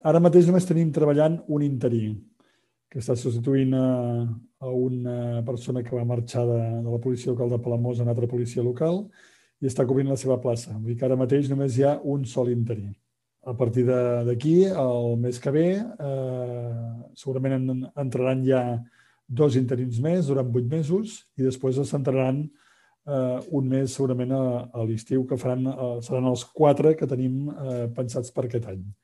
Ràdio Palamós ha recollit les explicacions que Alfons Bartolomé va donar al Ple sobre la planificació d’aquest any. El primer interí ja forma part de la plantilla, el mes que ve en vindran dos mes i el quart a l’estiu.